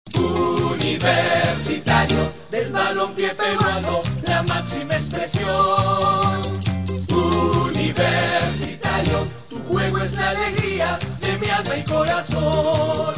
polka_u.mp3